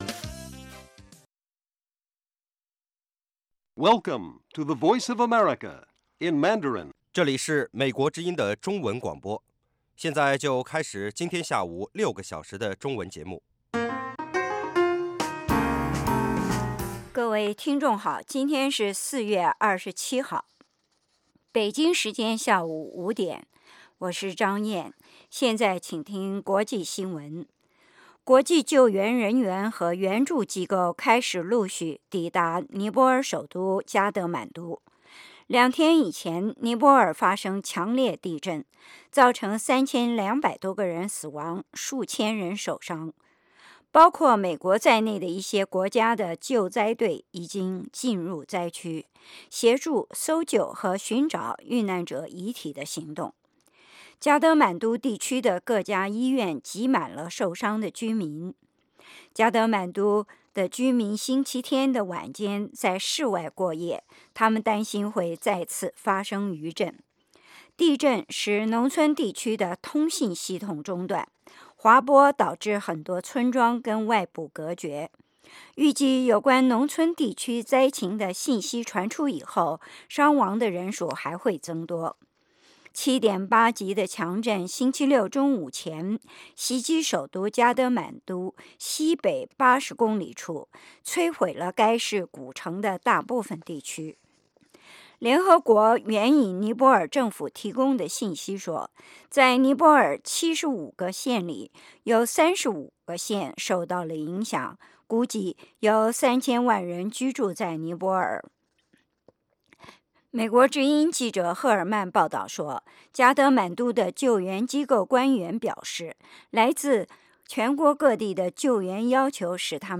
北京时间下午5-6点广播节目。 内容包括国际新闻和美语训练班（学个词， 美国习惯用语，美语怎么说，英语三级跳， 礼节美语以及体育美语）